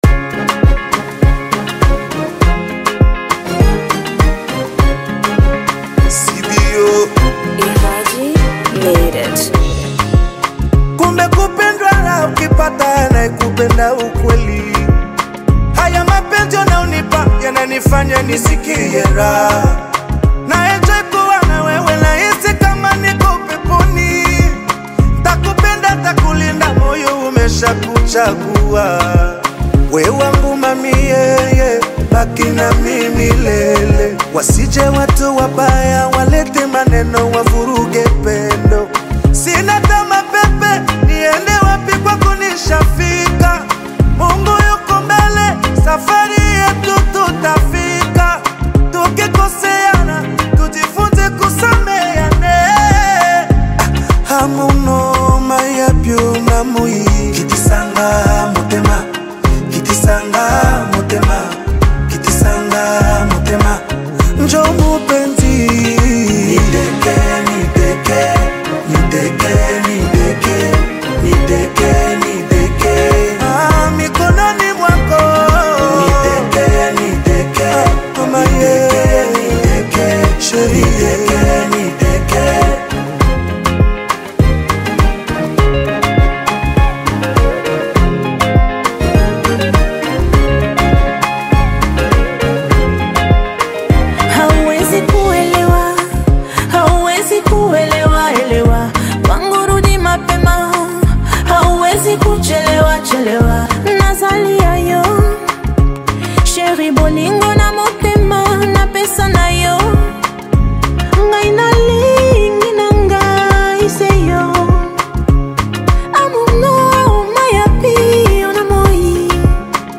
fans of the taarab genre have a new reason to rejoice
renowned for her soulful voice and emotive delivery
rich vocals and deep Swahili lyrical expression